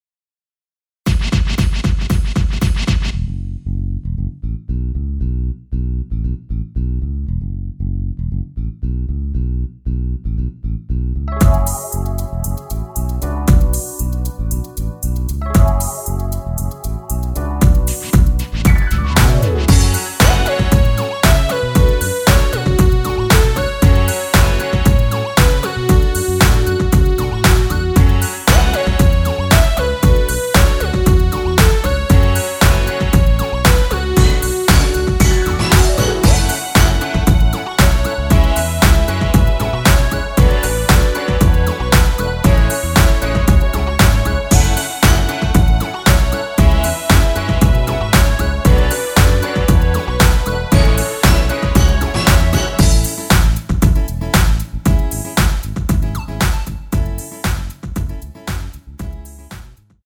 F#m
앞부분30초, 뒷부분30초씩 편집해서 올려 드리고 있습니다.